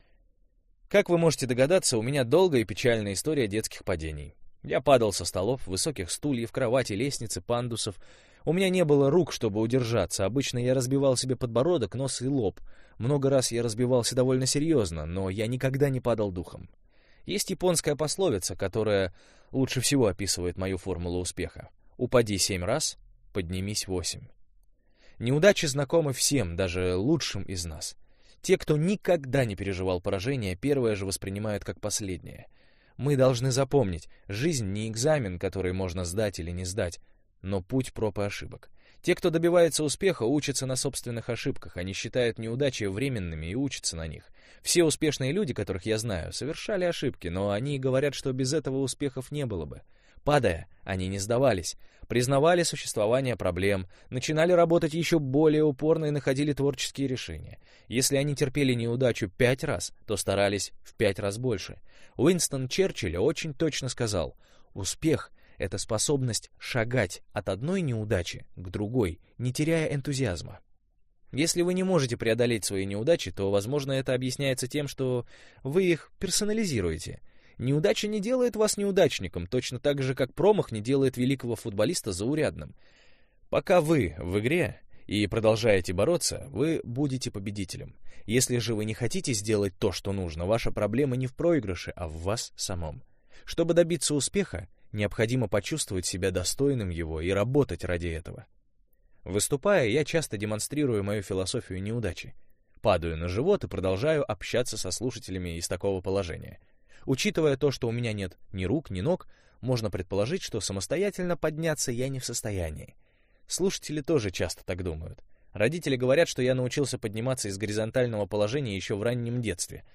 Аудиокнига Жизнь без границ.